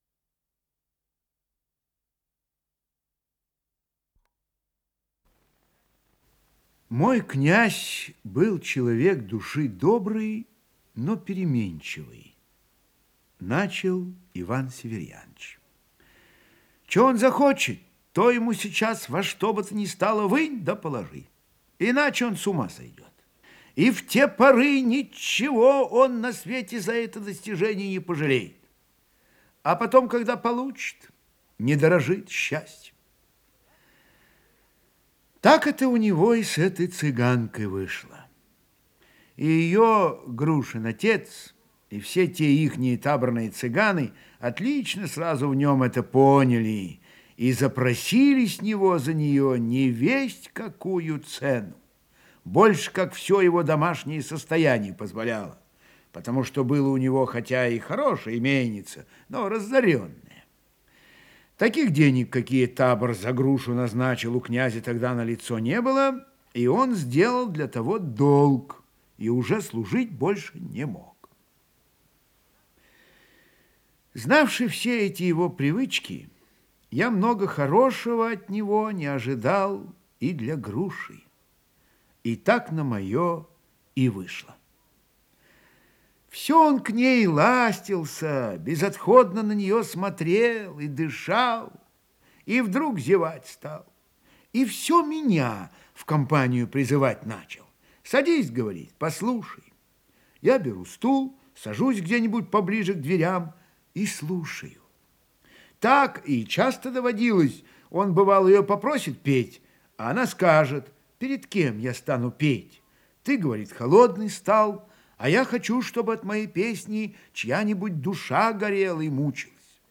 Исполнитель: Михаил Ульянов - чтение